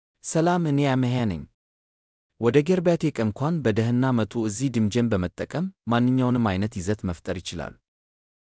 AmehaMale Amharic AI voice
Ameha is a male AI voice for Amharic (Ethiopia).
Voice sample
Listen to Ameha's male Amharic voice.
Male
Ameha delivers clear pronunciation with authentic Ethiopia Amharic intonation, making your content sound professionally produced.